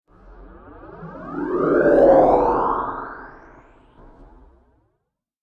دانلود آهنگ کشتی 3 از افکت صوتی حمل و نقل
جلوه های صوتی
دانلود صدای کشتی 3 از ساعد نیوز با لینک مستقیم و کیفیت بالا